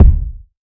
• Boomy Kick One Shot D Key 02.wav
Royality free bass drum one shot tuned to the D note. Loudest frequency: 92Hz
boomy-kick-one-shot-d-key-02-Vng.wav